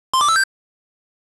pickupkey.wav